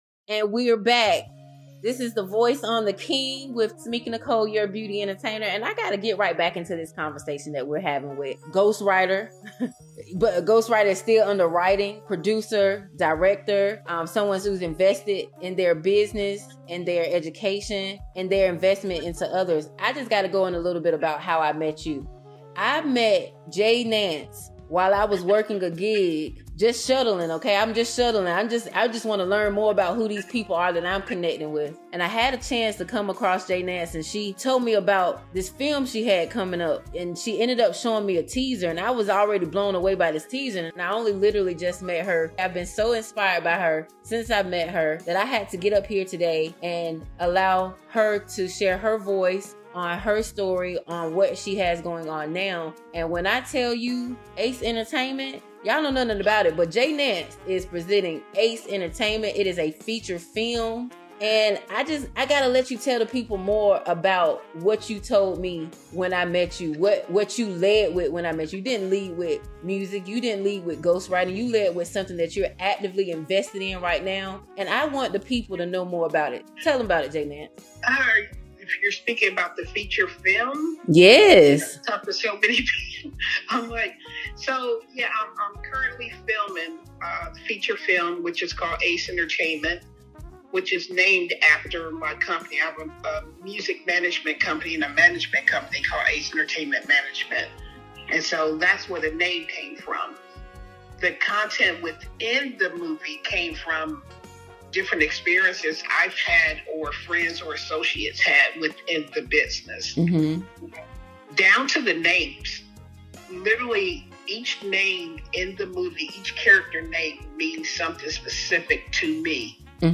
Guest of the Week